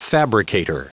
Prononciation du mot fabricator en anglais (fichier audio)
Prononciation du mot : fabricator